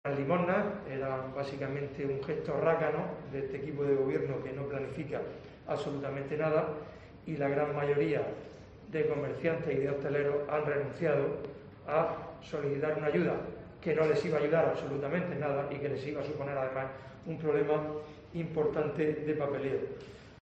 Fulgencio Gil, portavoz PP